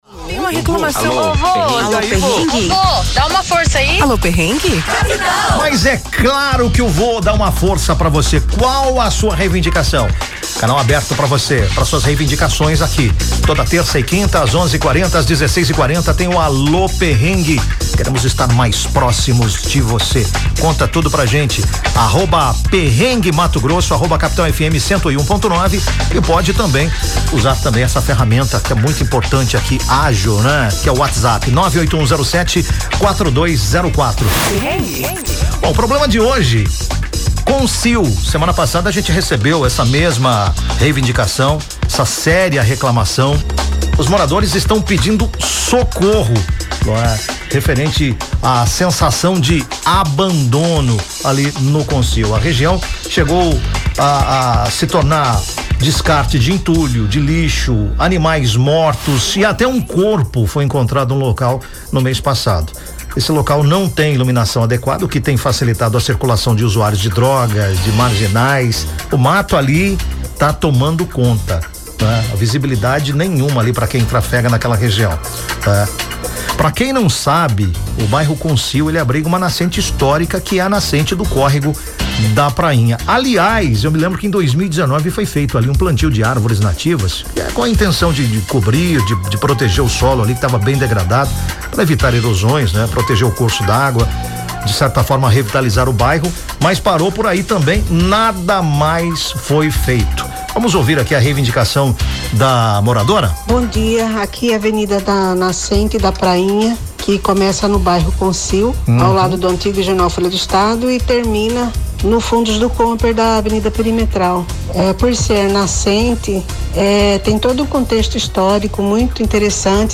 No Alô Perrengue na Capital desta terça-feira (02.02), uma moradora denuncia que na Avenida Tenente, próximo a um córrego que corta a região, diversas pessoas utilizam uma mata para cometer assaltos, jogar lixo e desovar corpos de animais.
Resultado de uma parceria entre a Rádio Capital FM 101.9 e a página do Instagram “Perrengue Mato Grosso”, o ‘Alô Perrengue na Capital’ vai ao ar na programação da rádio todas as terças e quintas-feiras, às 11h40, e às 16h40.